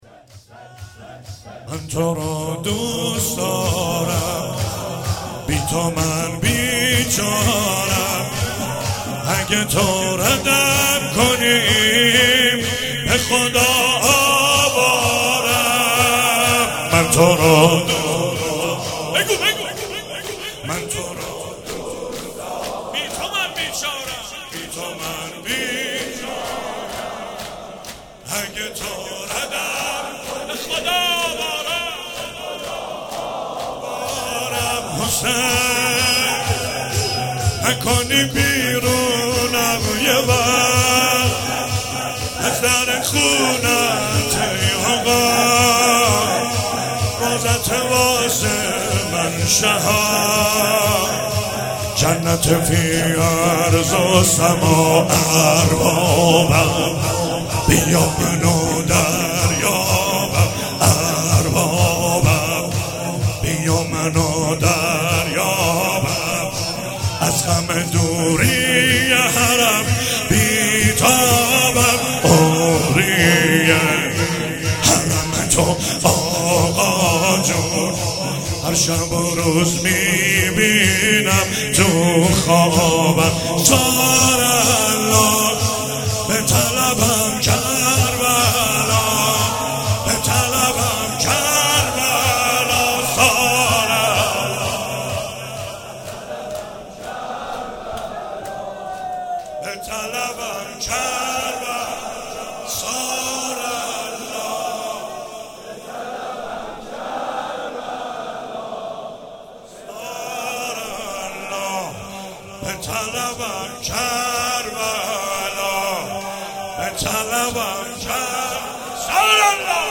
شب سوم رمضان 95، حاح محمدرضا طاهری
04 heiat alamdar mashhad.mp3